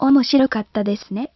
CHATR random-access synthesis)